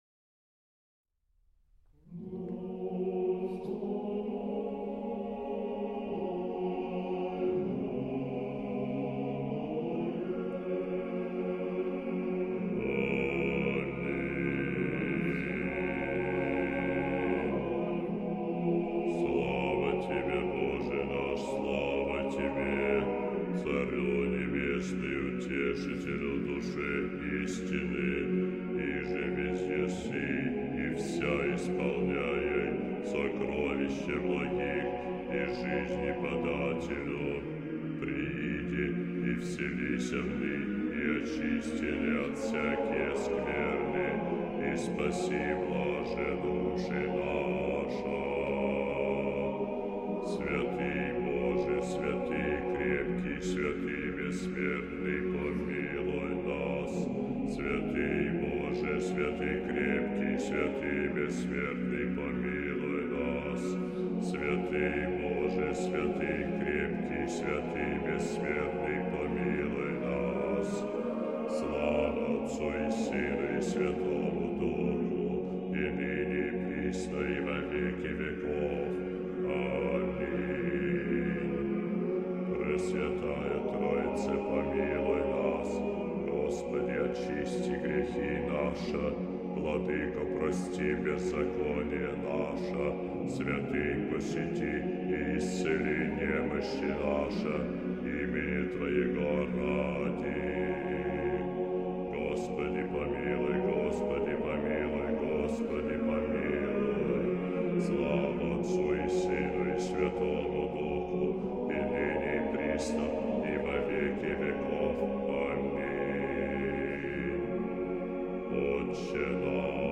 А тут Профундо.То есть басы еще на октаву ниже поют.
Ортокс хор.Басы Профундо.